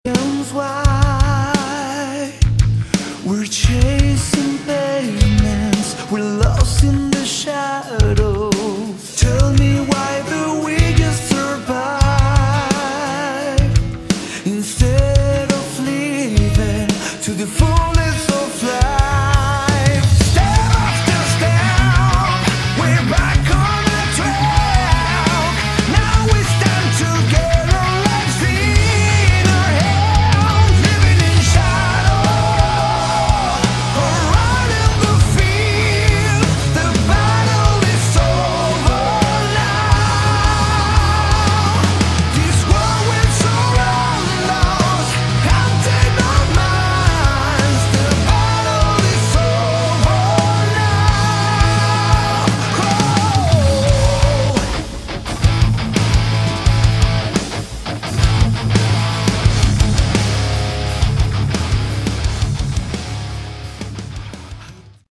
Category: Melodic Metal
vocals
guitar
keyboards, backing vocals
bass
drums